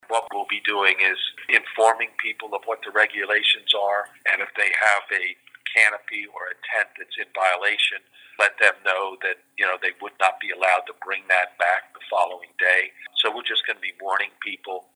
Mayor Meehan adds that they’ll be giving out warnings for any offenses…